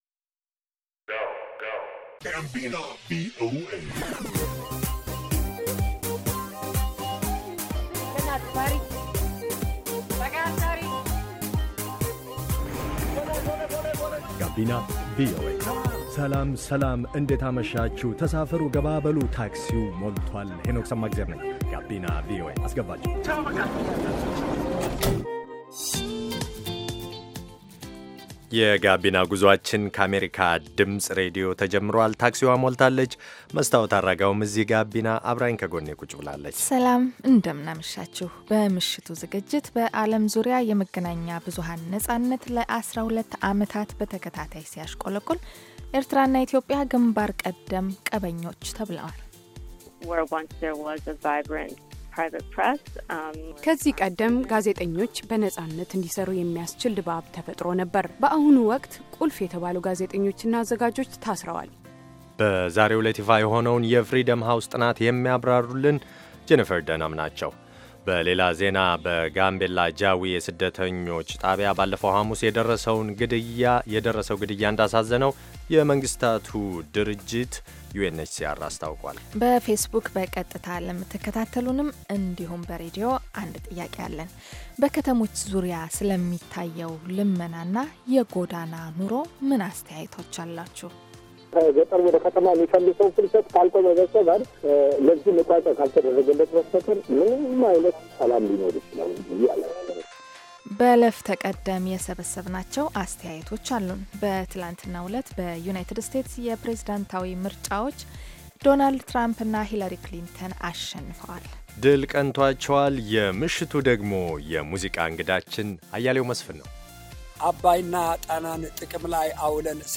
Gabina VOA is designed to be an infotainment youth radio show broadcasting to Ethiopia and Eritrea in the Amharic language. The show brings varied perspectives on issues concerning young people in the Horn of Africa region.